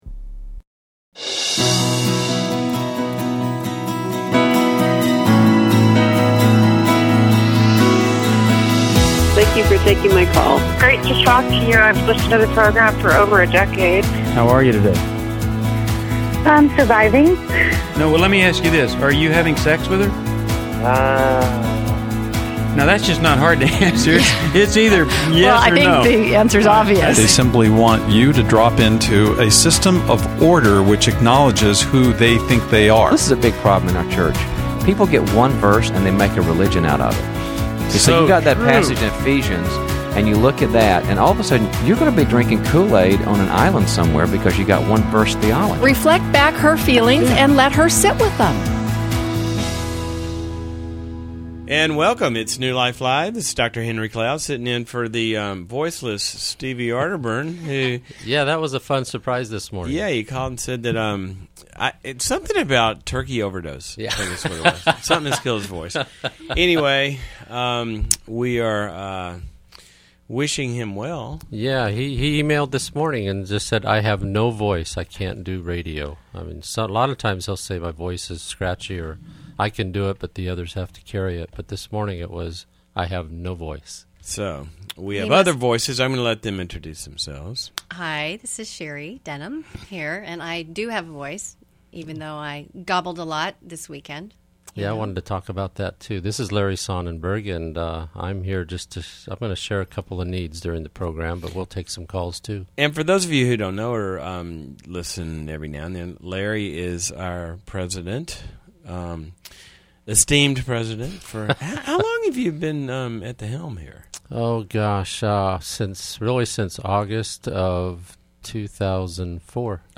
Caller Questions: Is it wise to marry into a crazy family?